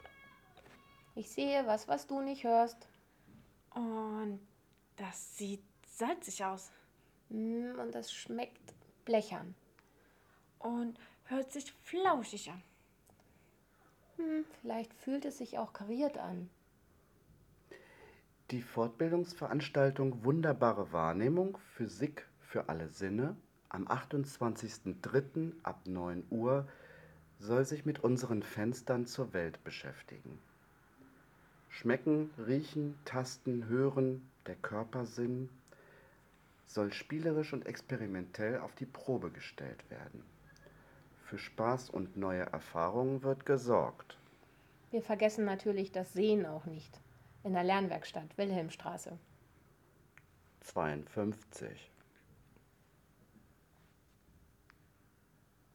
Das Werkstattteam berichtet in kurzen Audiobeiträgen über alles Wissenswerte rund um einzelne Veranstaltungen.